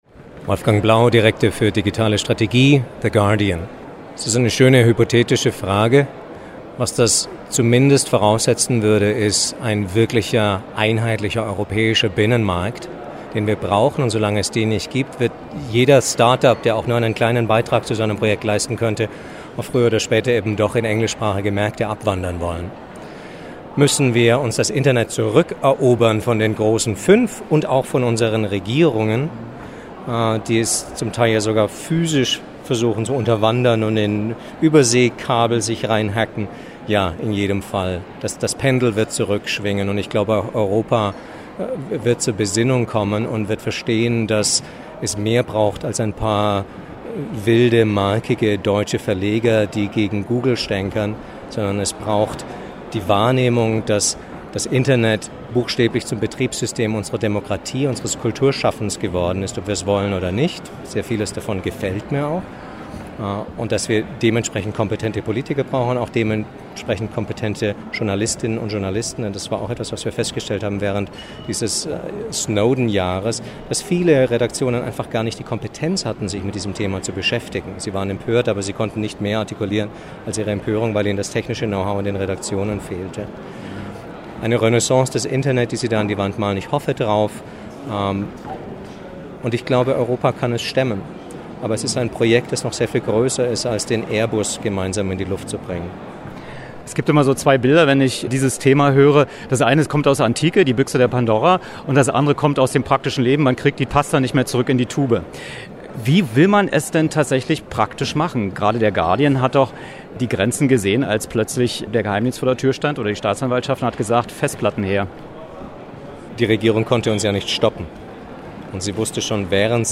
Was: Interview
Wo: M100 Sanssouci Colloquium, Orangerie Sanssouci, Potsdam